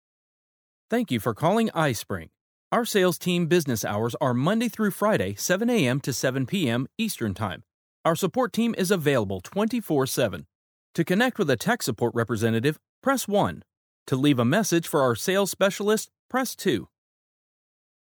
iSpring Sales Team voicemail.mp3